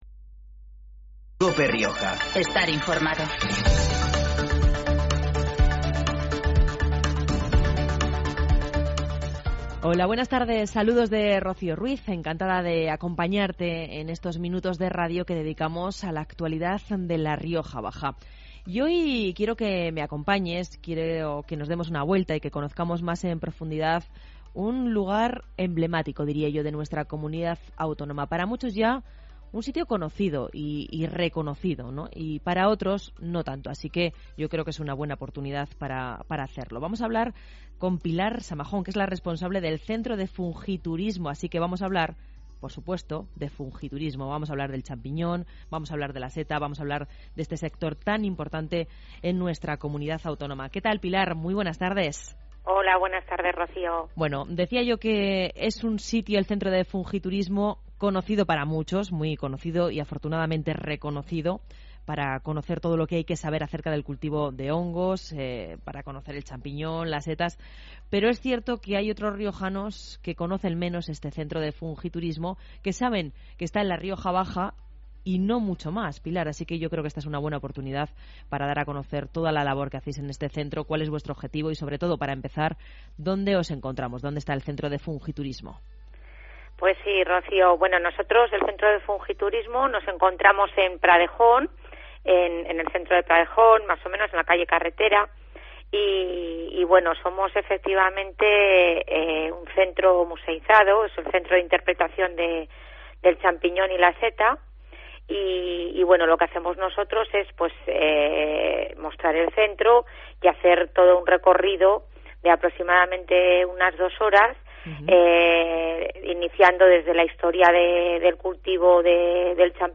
Con ella hemos hablado de esta producción, y del cultivo de hongos en general, y de lo que supone para La Rioja Baja.